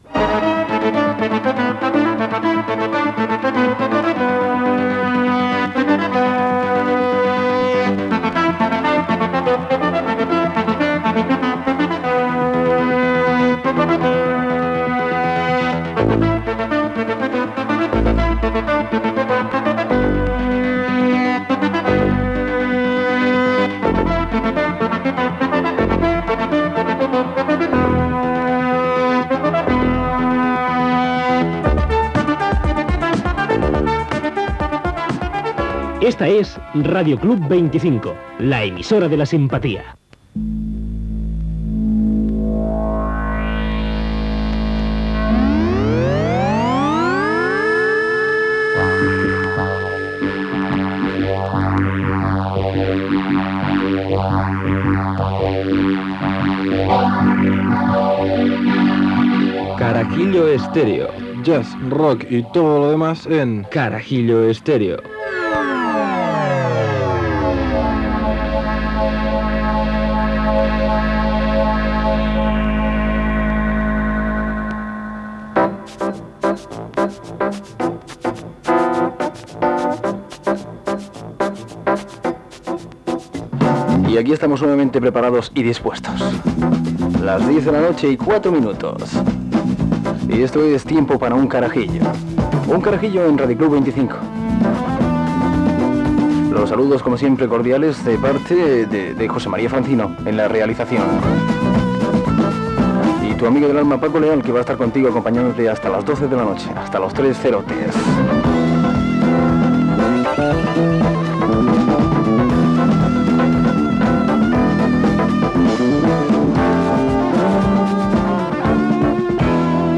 Identificació de l'emissora, careta del programa, equip, presentació, possibilitat d'allargar la durada del programa, música de jazz
Musical
FM